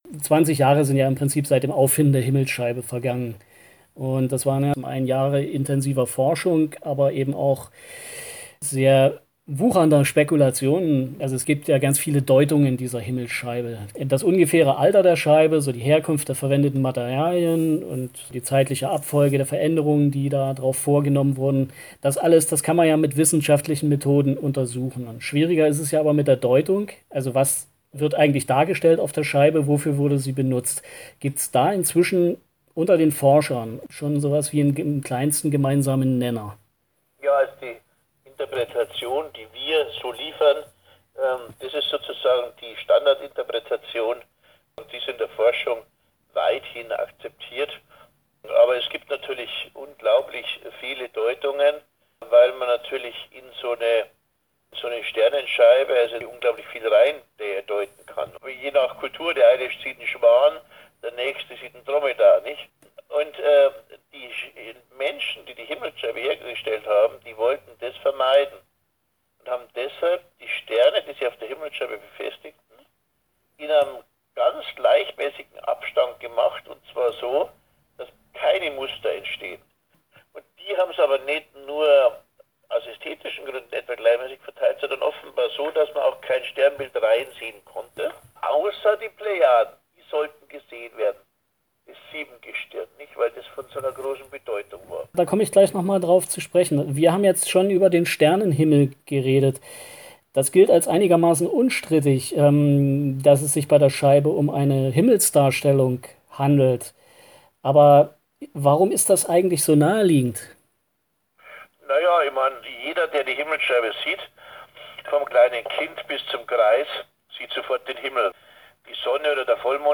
Interview-Harald-Meller-cutfix.mp3